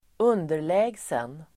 Uttal: [²'un:der_lä:gsen]